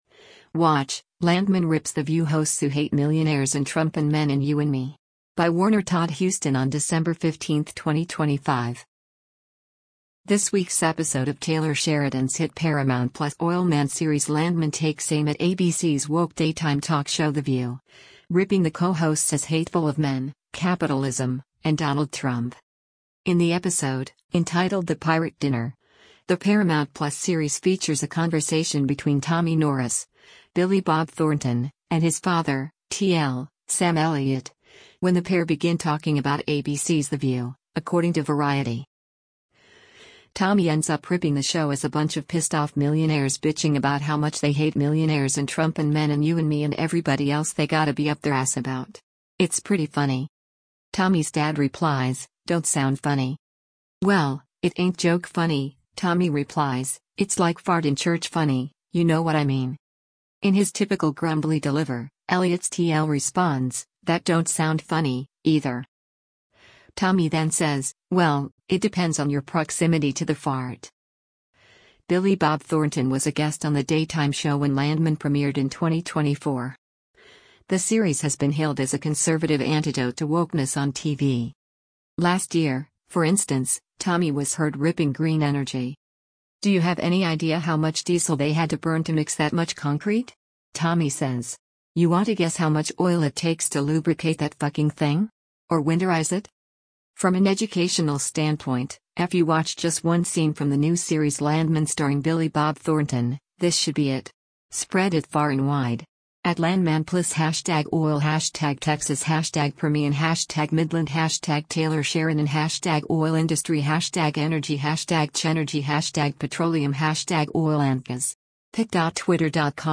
In the episode, entitled The Pirate Dinner, the Paramount+ series features a conversation between Tommy Norris (Billy Bob Thornton) and his father, T.L. (Sam Elliott), when the pair begin talking about ABC’s The View, according to Variety.